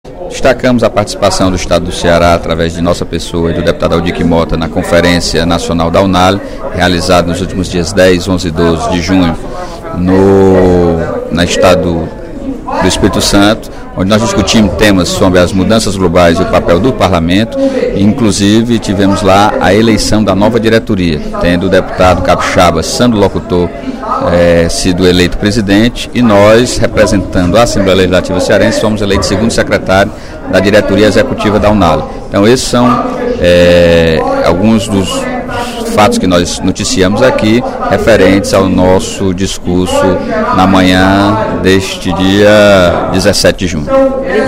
A 19ª Conferência Nacional dos Legisladores e Legislativos, promovida pela União Nacional dos Legisladores e Legislativos Estaduais (Unale), realizada entre os dias 10 e 12 deste mês, foi tema de pronunciamento do deputado Sérgio Aguiar (Pros) no primeiro expediente da sessão plenária desta quarta-feira (17/06).